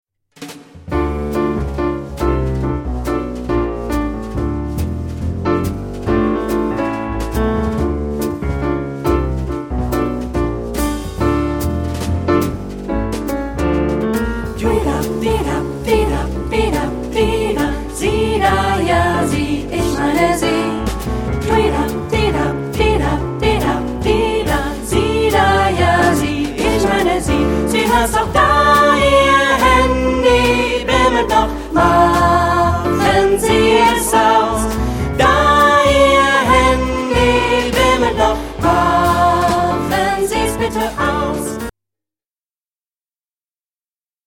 Tonart(en): d-moll